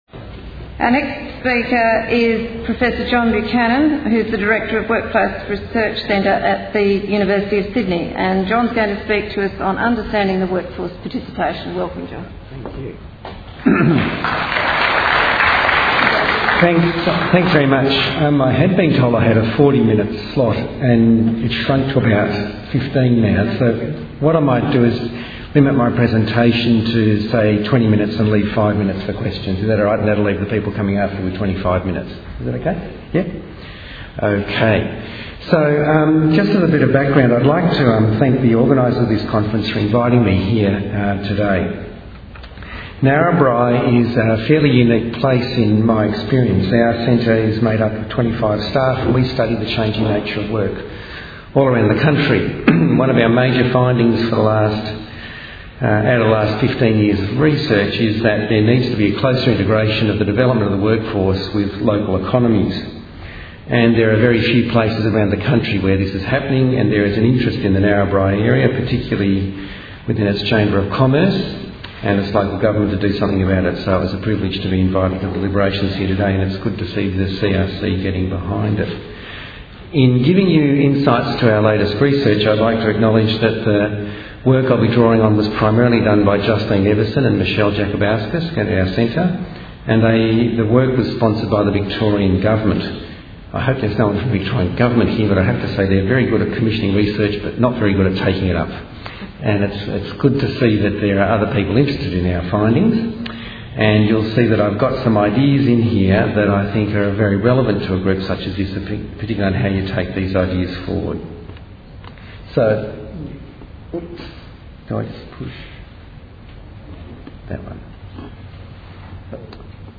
Understanding workforce participation.- Sustaining Rural Communities Conference 2010 Presentation | Inside Cotton